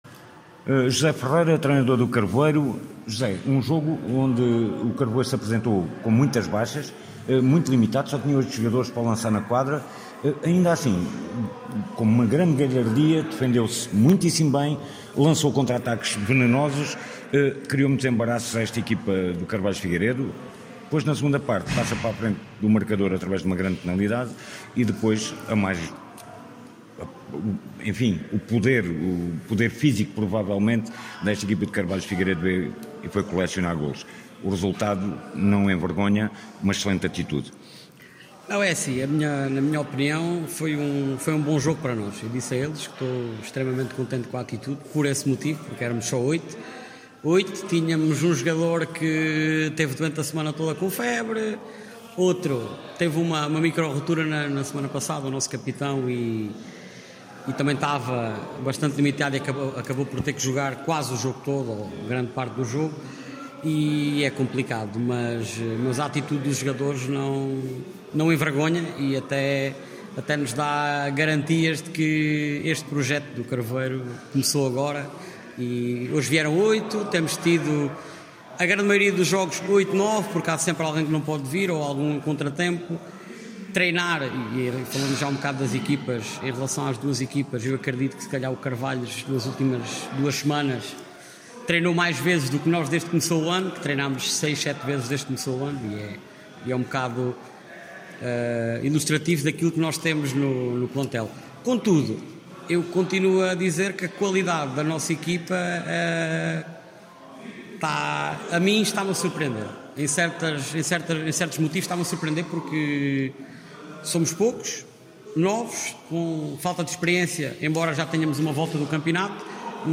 No final fomos ouvir os técnicos de ambas as equipas: